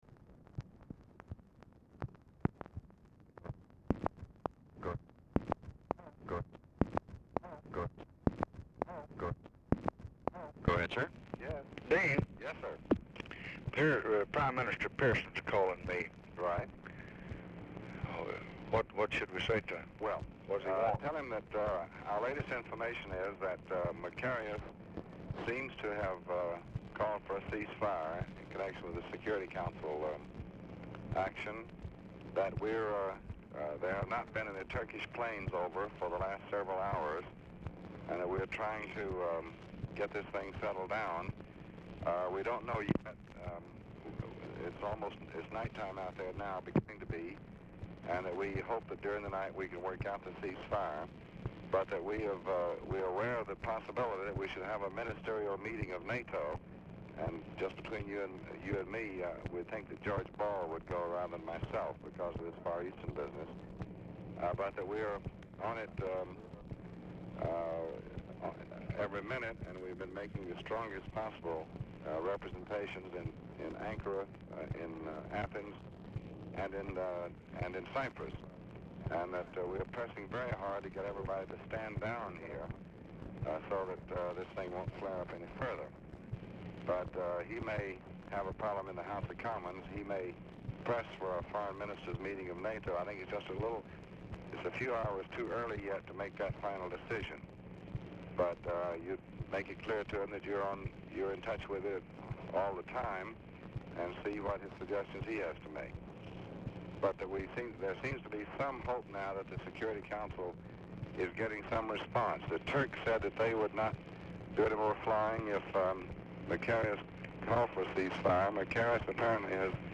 LBJ Ranch, near Stonewall, Texas
Telephone conversation
Dictation belt